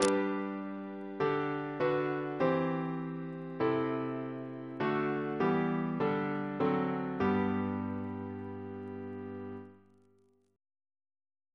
Single chant in G Composer: Edwin George Monk (1819-1900), Organist of York Minster Reference psalters: ACP: 259; H1940: 604; H1982: S4 S230